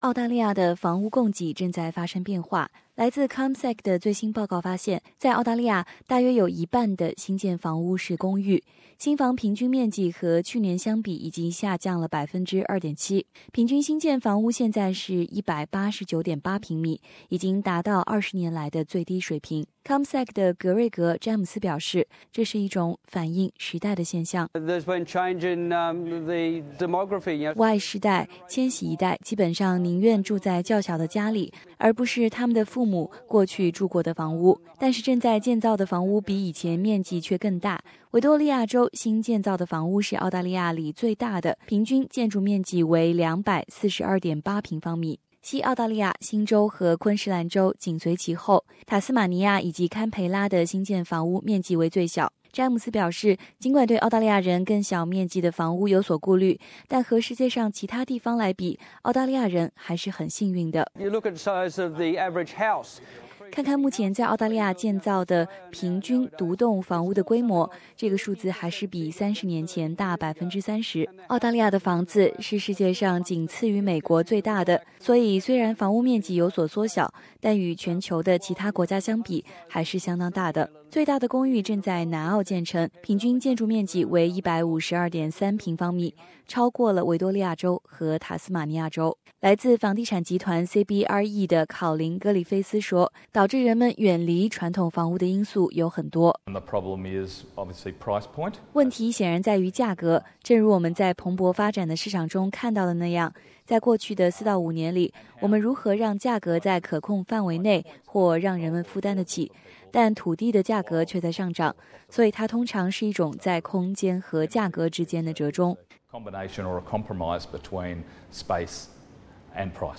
Source: AAP SBS 普通话电台 View Podcast Series Follow and Subscribe Apple Podcasts YouTube Spotify Download (1.36MB) Download the SBS Audio app Available on iOS and Android 由于公寓建造数量的上升，澳大利亚家庭的平均居住面积有所下降。